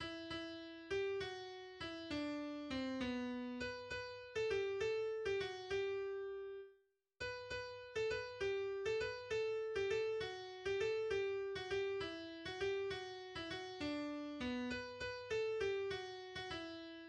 Text & Melodie Volkslied, 17.